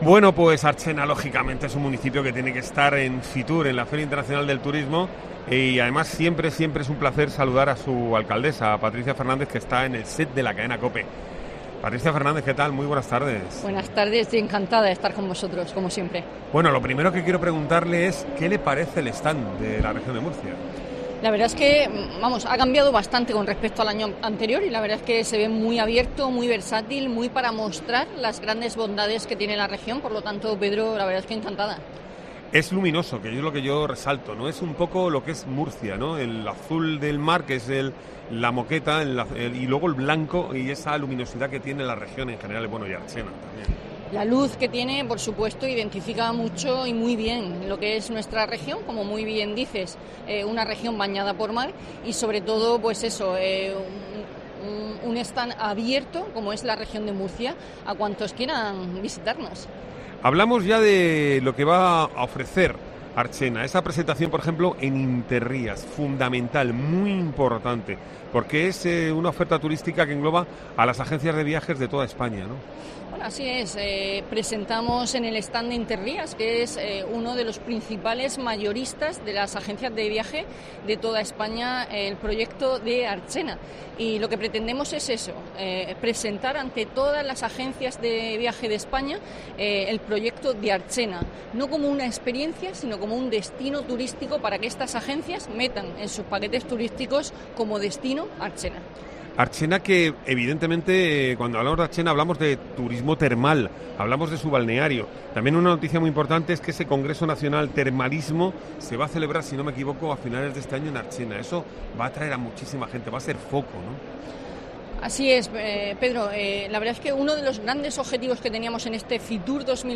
Patricia Fernández, alcaldesa de Archena, en COPE Región de Murcia en FITUR
Patricia Fernández ha conversado en el set de Cope Región de Murcia de la oferta de su municipio.